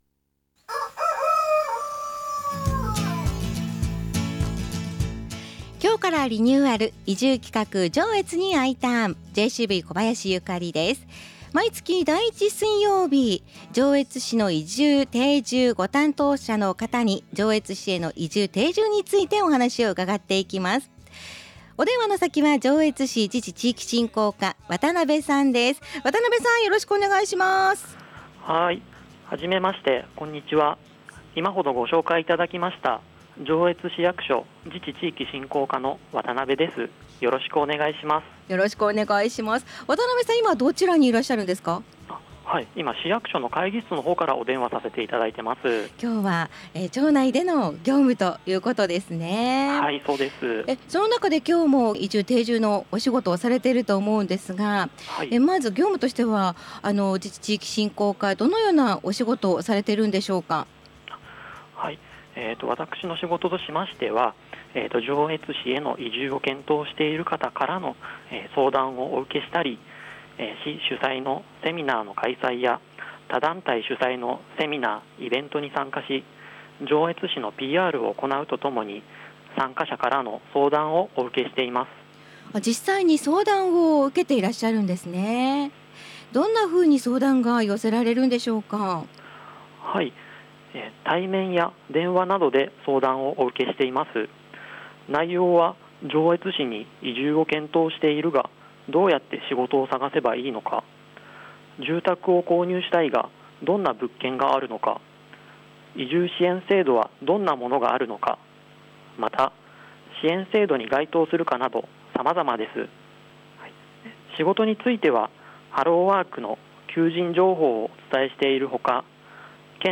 今回からコーナーリニューアルです✨ スタジオから「すまいるカフェ」パーソナリティの